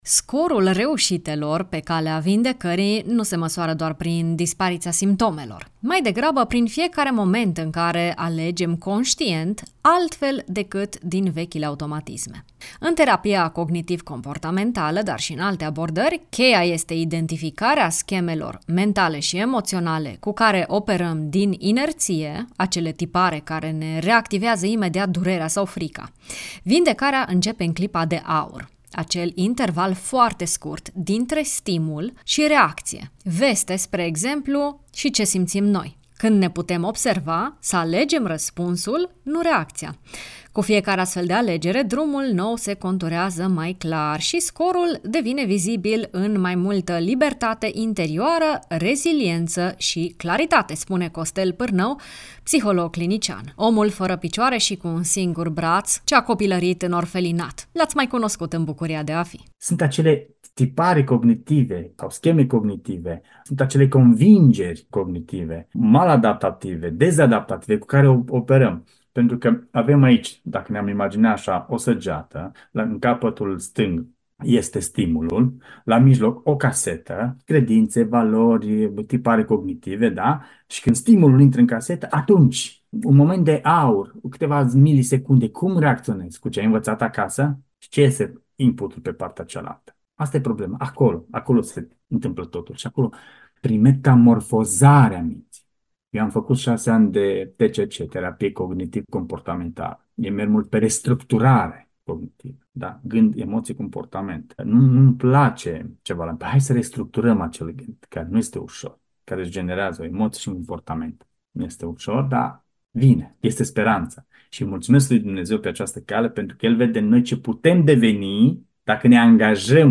psiholog clinician: